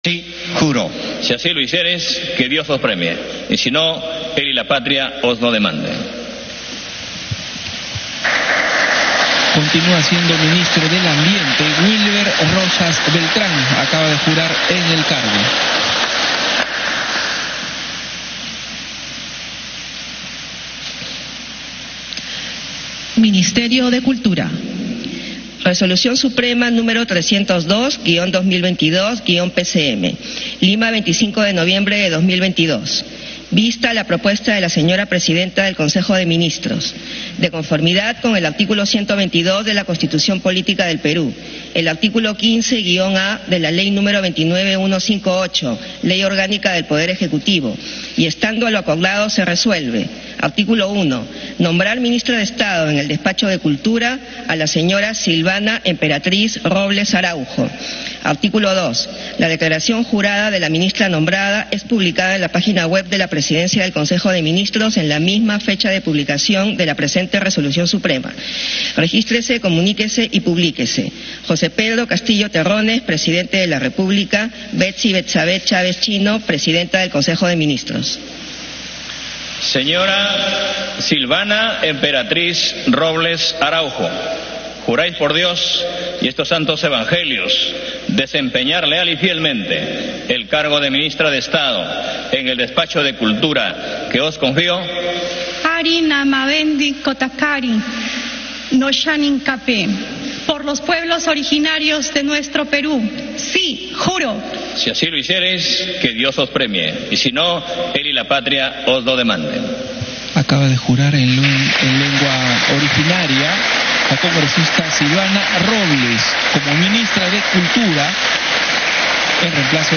Enlace en vivo desde Palacio de Gobierno donde se lleva a cabo la juramentación del nuevo Gabinete Ministerial encabezado por la presidenta del Consejo de Ministros, Betssy Chávez.